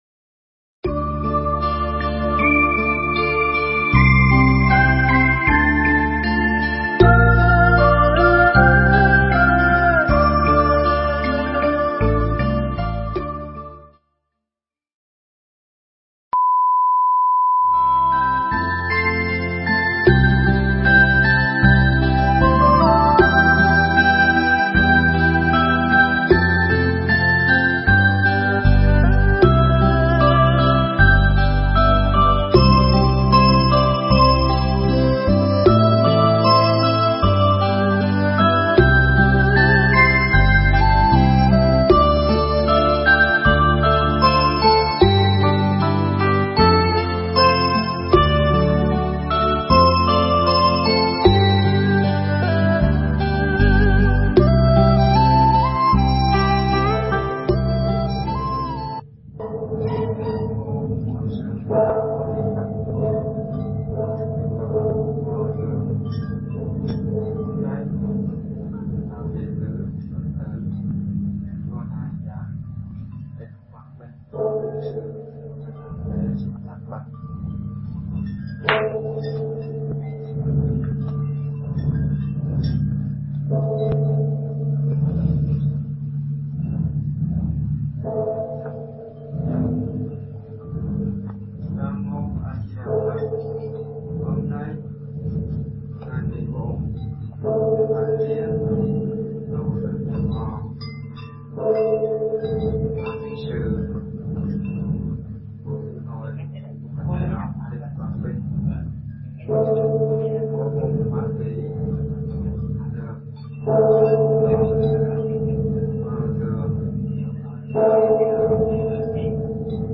Mp3 An Lạc Trong Ta – Thuyết pháp